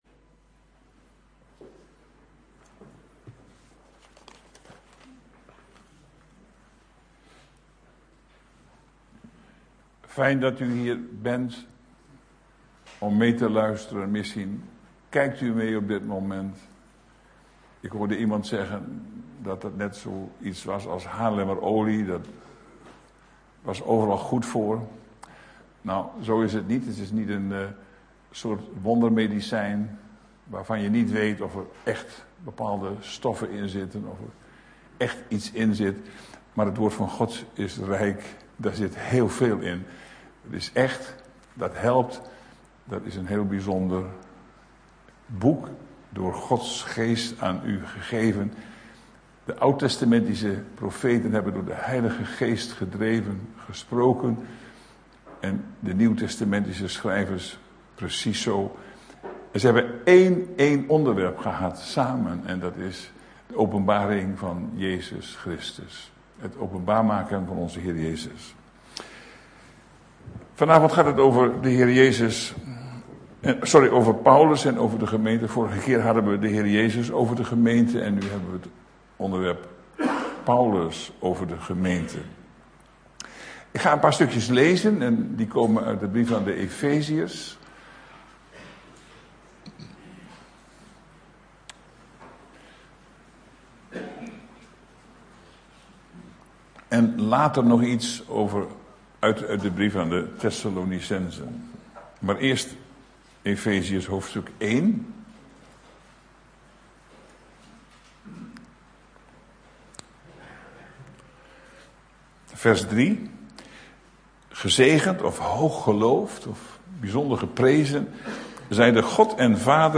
AfspelenDeze preek is onderdeel van de serie:"Profetie"DownloadAudiobestand (MP3)